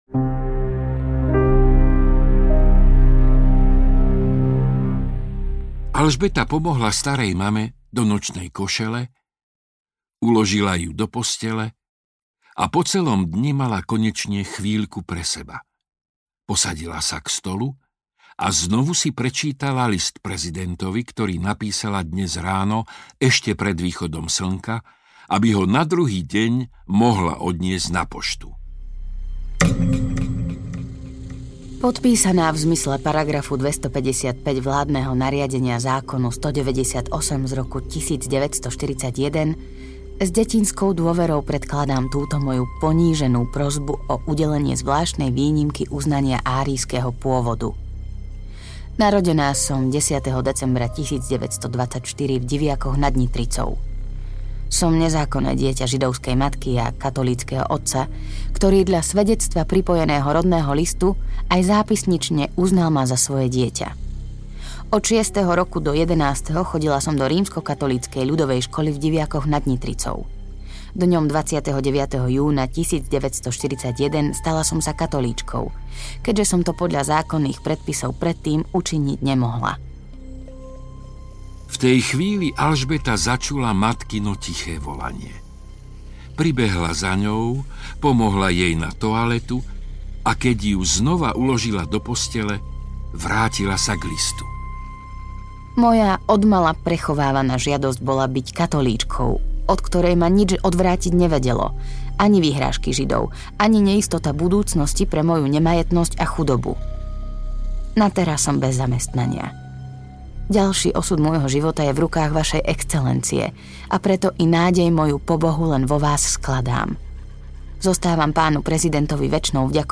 Slovutný pán prezident audiokniha
Ukázka z knihy